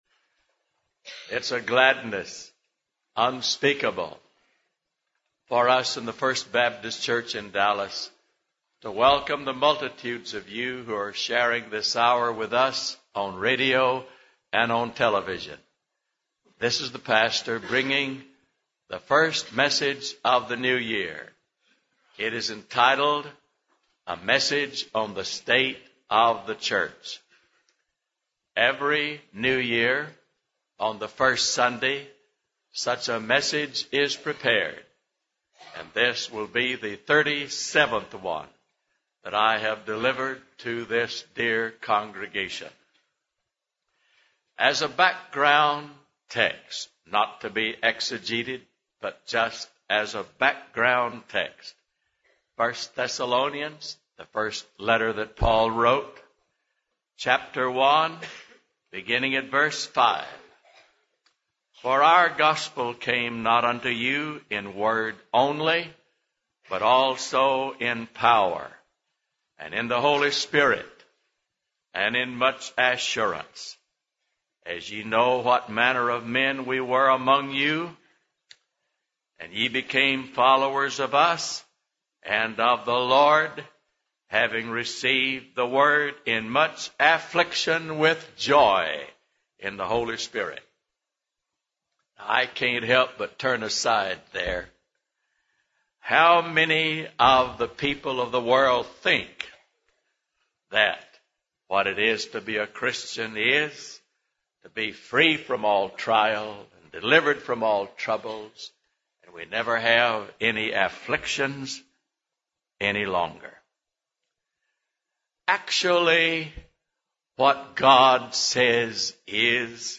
Annual State of the Church Message – W. A. Criswell Sermon Library